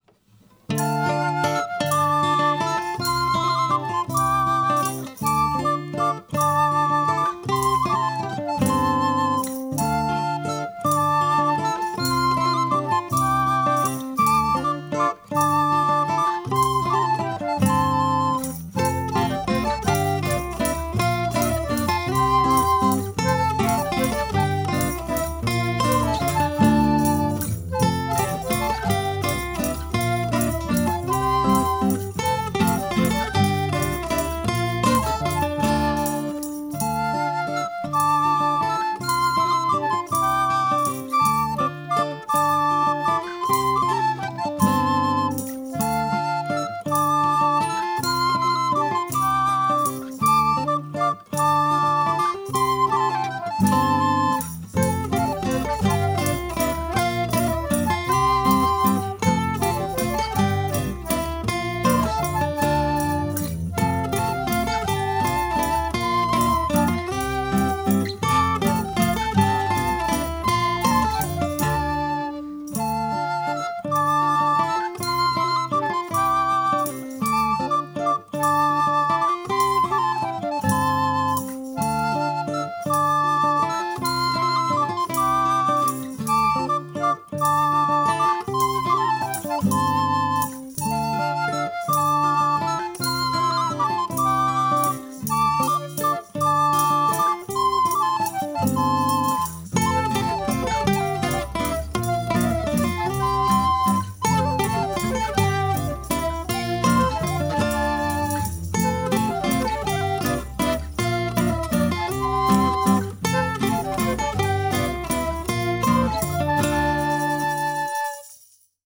Evolutive Background music